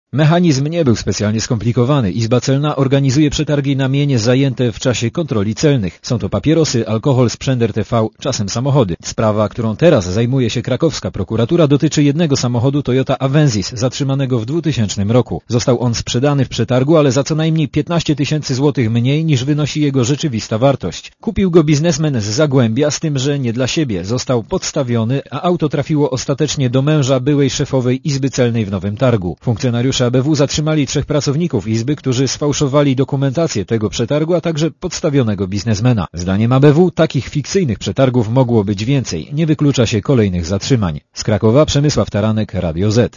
Posluchaj relacji reportera Radia Zet (167 KB)